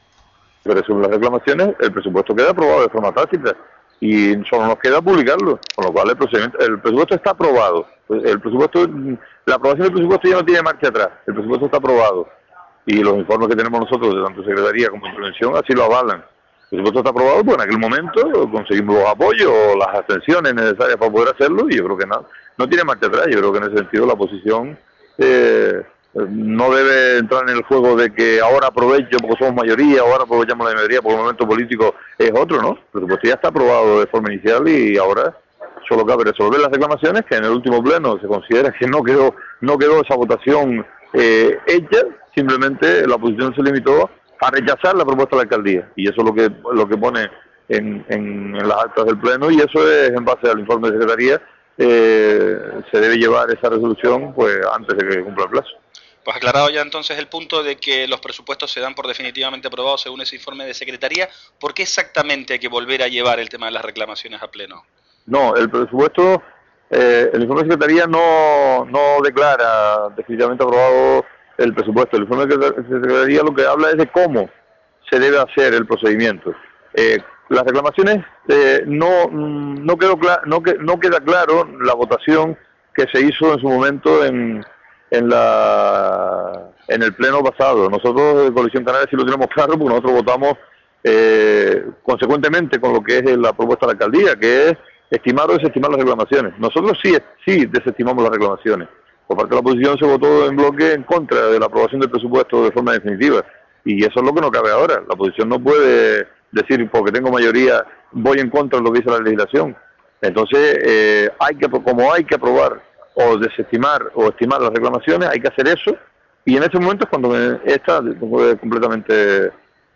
/ Escuche al concejal de Hacienda Luciano Pérez haciendo clik aquí /